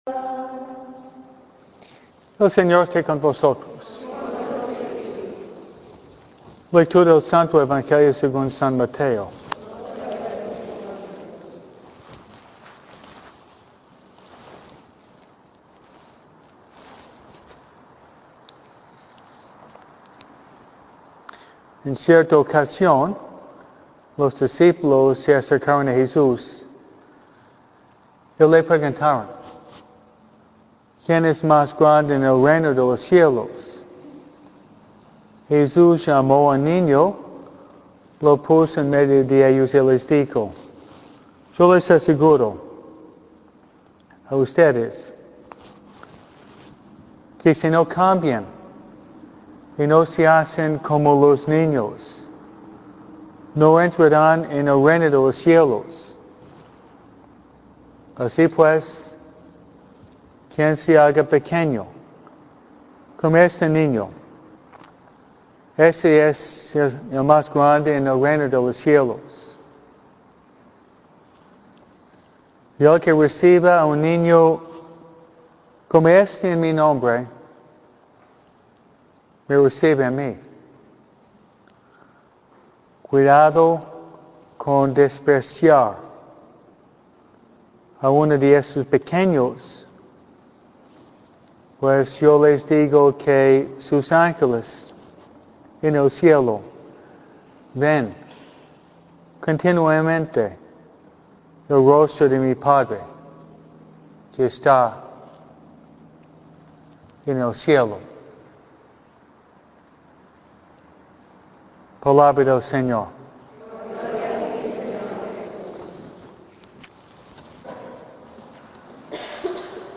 MISA – ANGELES DE DIOS